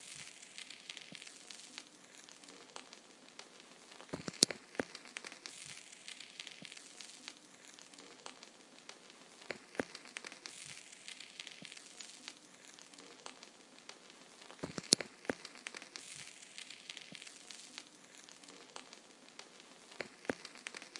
描述：记录旋转记录静态裂纹乙烯基转盘表面噪音噪音
Tag: 乙烯 静态 转盘 记录 裂纹 表面噪声 纺记录 噪音